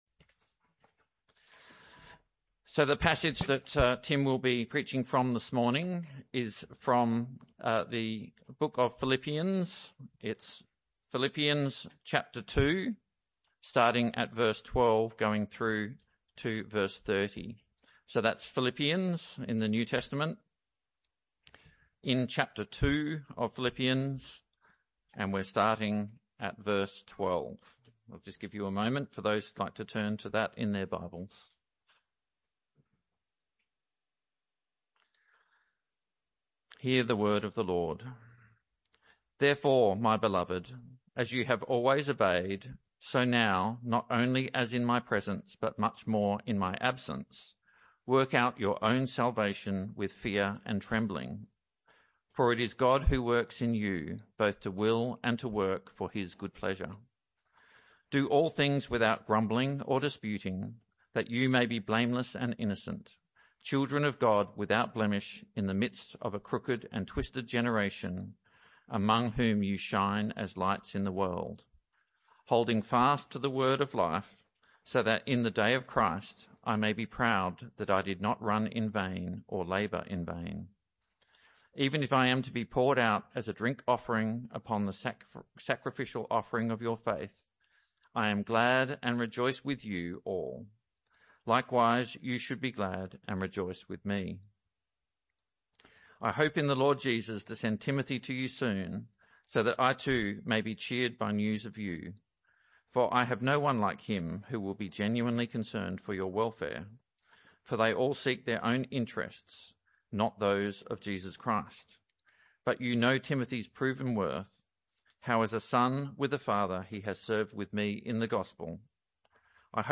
This talk was a one-off talk in the AM Service.